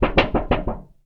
metal_tin_impacts_wobble_bend_01.wav